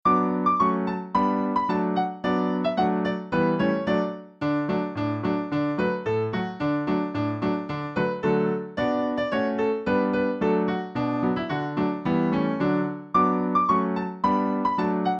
Folk Song Lyrics